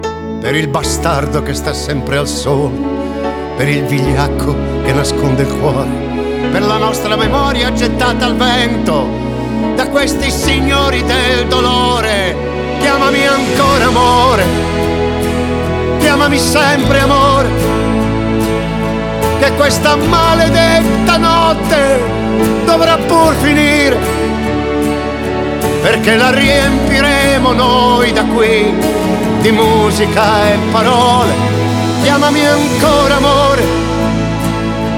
Жанр: Поп / Рок